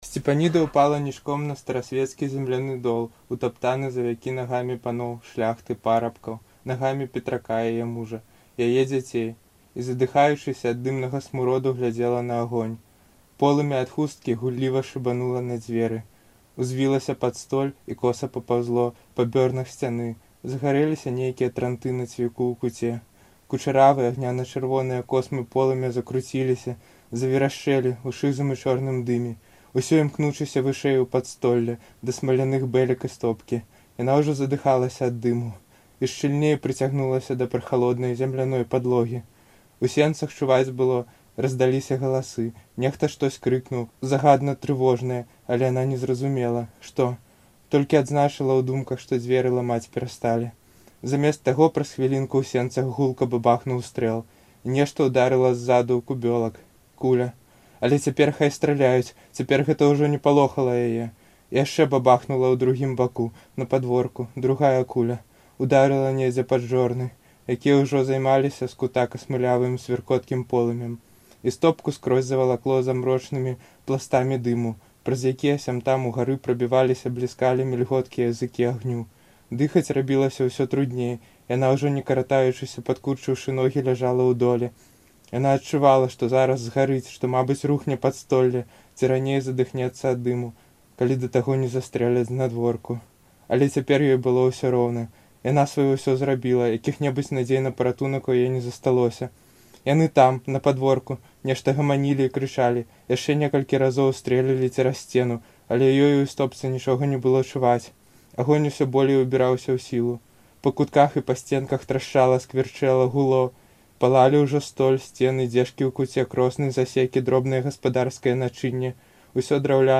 Штодня ў чэрвені выпускнікі Беларускага гуманітарнага ліцэю (цяперашнія і колішнія) чытаюць радкі з улюблёных быкаўскіх твораў.